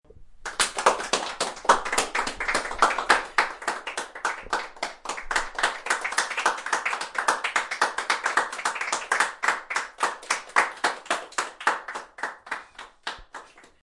Download Clapping sound effect for free.
Clapping